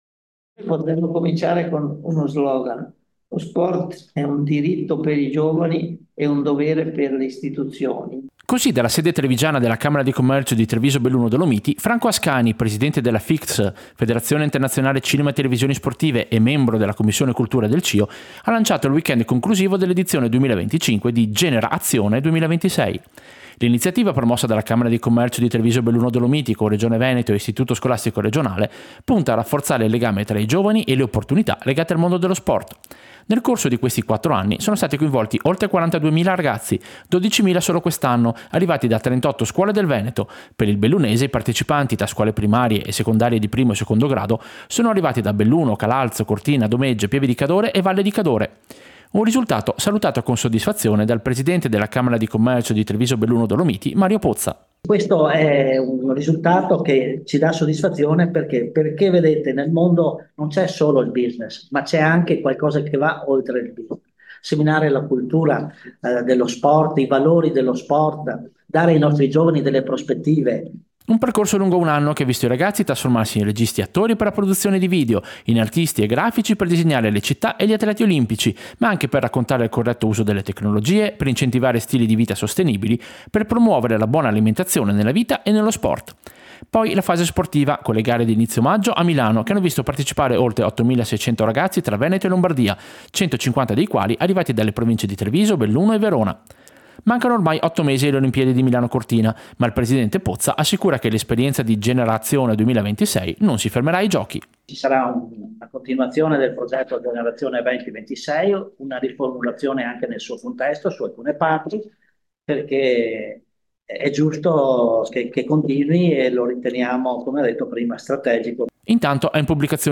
Servizio-Quarta-edizione-GenerAzione-2026.mp3